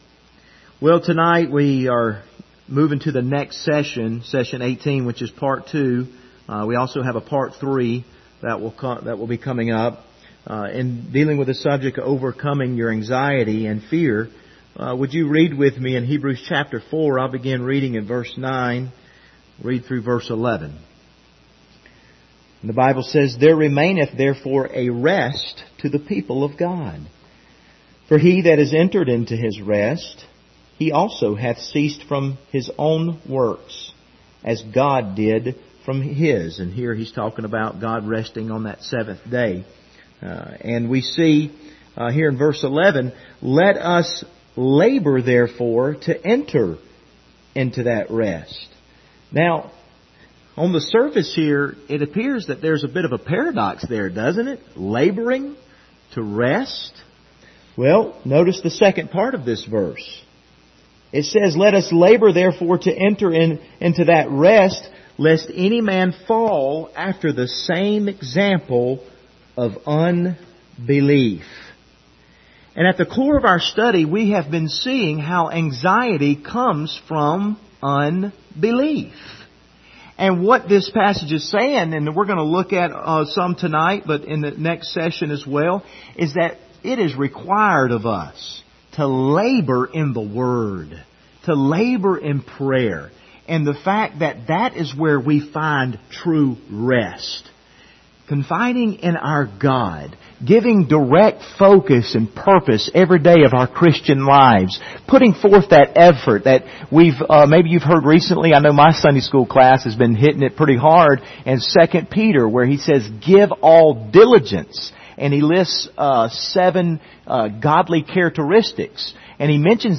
Service Type: Wednesday Evening Topics: anxiety , obsessions